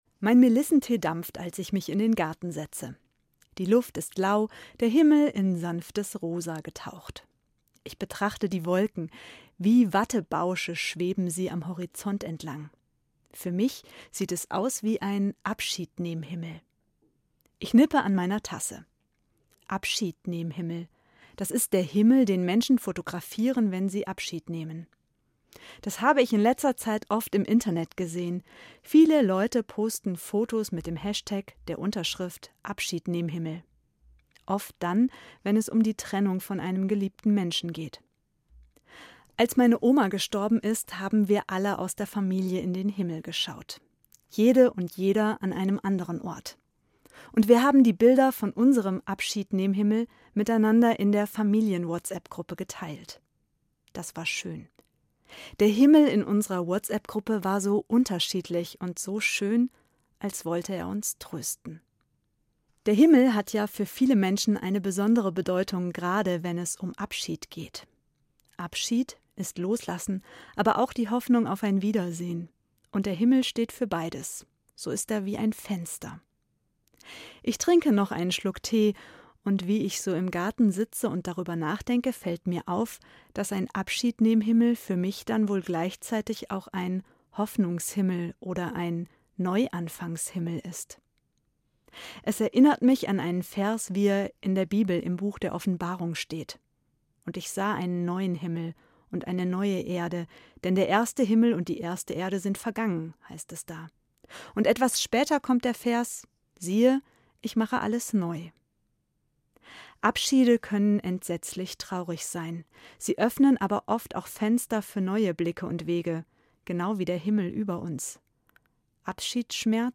Ev. Pfarrerin in Fulda